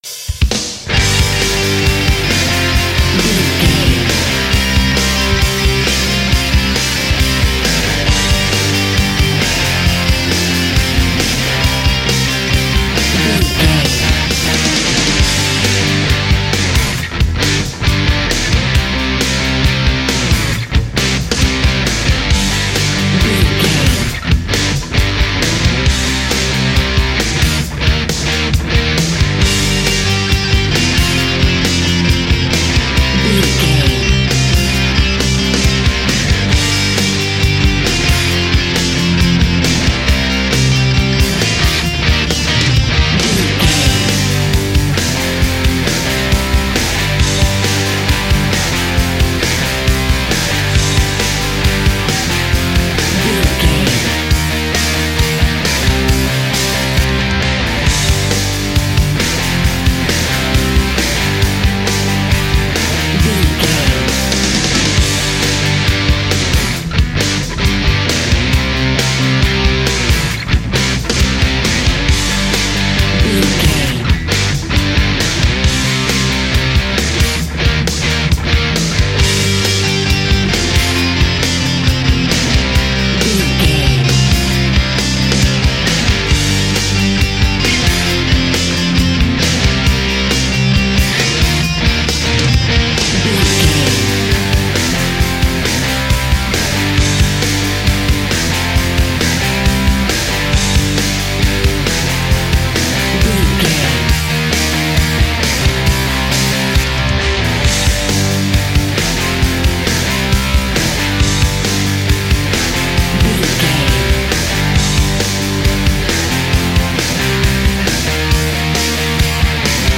Epic / Action
Aeolian/Minor
Fast
drums
electric guitar
bass guitar
Sports Rock
hard rock
aggressive
energetic
intense
nu metal
alternative metal